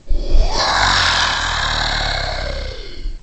龙的声音 " 龙的咆哮呼吸声 4
描述：为制作史瑞克而制作的龙声。使用Audacity录制并扭曲了扮演龙的女演员的声音。
Tag: 生物 发声 怪物